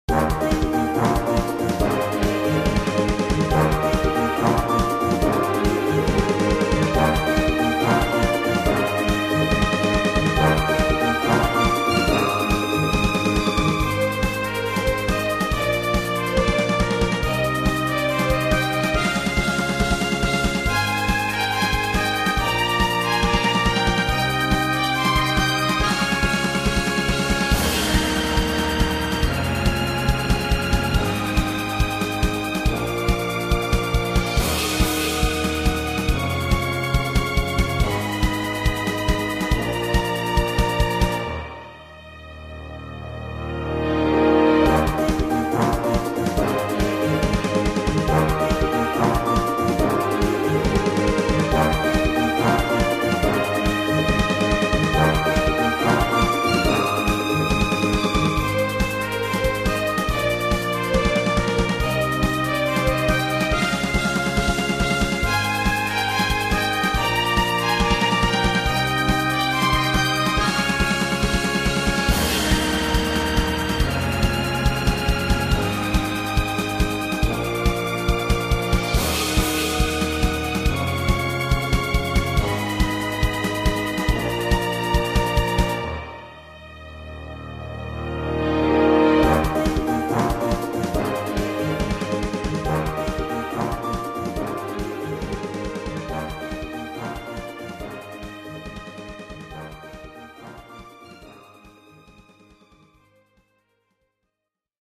Its really really bouncy and happy.